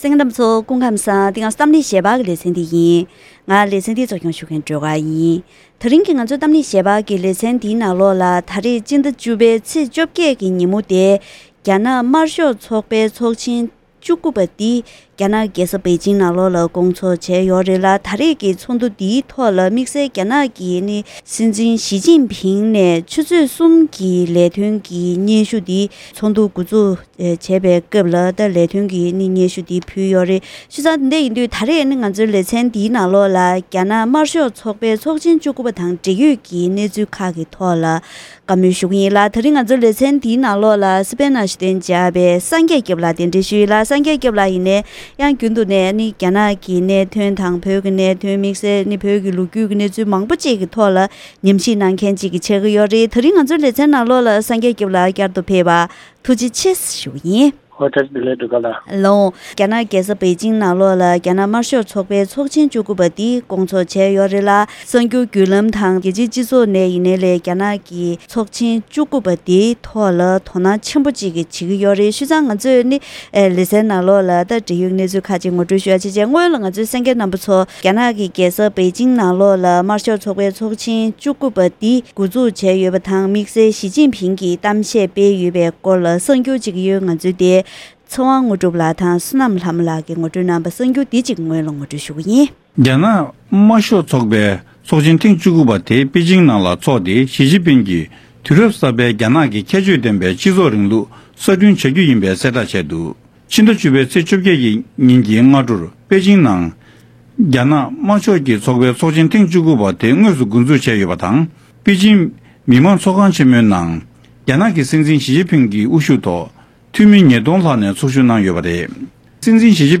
ད་རིང་གི་གཏམ་གླེང་ཞལ་པར་ལེ་ཚན་ནང་རྒྱ་ནག་དམར་ཤོག་ཚོགས་པའི་ཚོགས་ཆེན་༡༩འདི་ད་རེས་པེ་ཅིང་དུ་སྐོང་ཚོགས་བྱས་ཡོད་པས། རྒྱ་ནག་གི་དཔོན་རིགས་ཞི་ཅིང་ཕིང་གི་གཏམ་བཤད་ཐོག་དབྱེ་ཞིབ་དང་། ཞི་ཅིང་ཕིང་གིས་འདས་པའི་ལོ་ལྔའི་ལས་ཡུན་རིང་བོད་ཐོག་འཛིན་པའི་སྲིད་ཇུས་ཀྱི་ཤུགས་རྐྱེན། དམིགས་བསལ་ཚོགས་ཆེན་སྐབས་བོད་ནང་དམ་བསྒྲགས་བྱས་ཡོད་པ་སོགས་ཀྱི་སྐོར་ལ་འབྲེལ་ཡོད་དང་བཀའ་མོལ་ཞུས་པ་ཞིག་གསན་རོགས་གནང་།